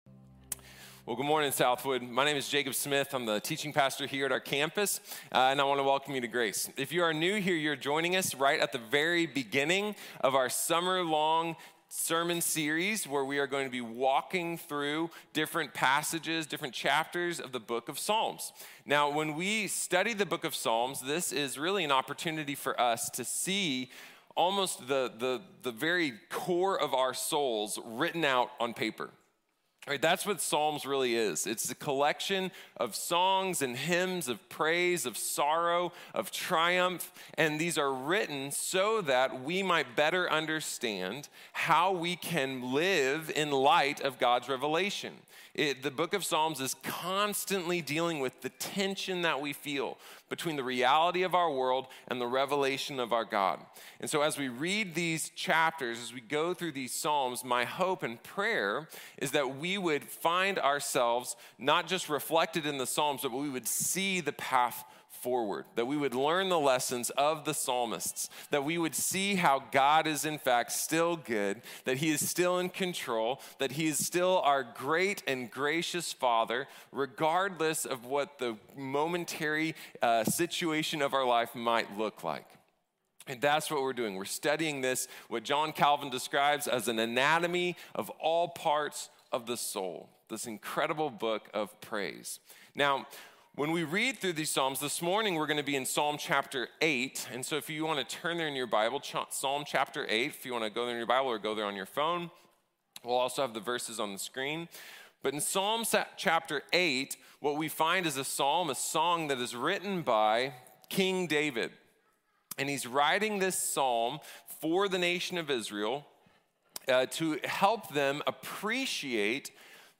God is Great | Sermon | Grace Bible Church